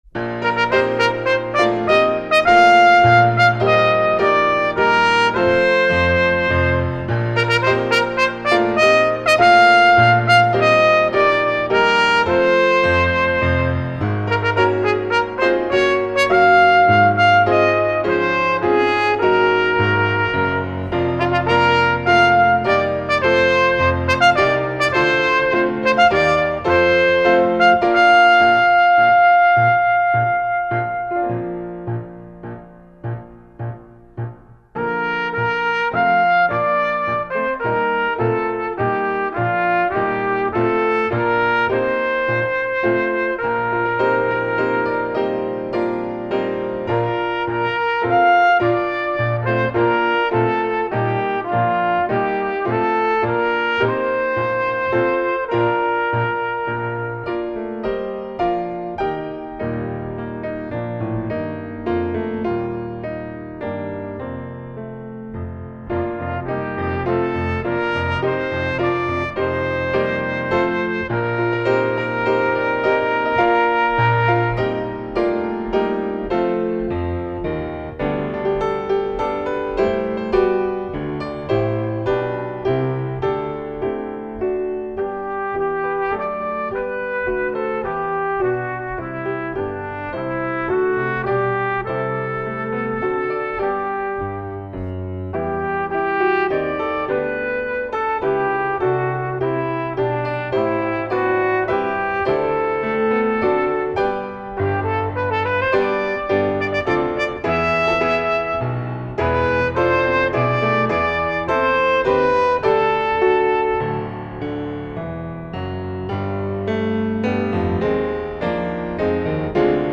Voicing: Brass and Piano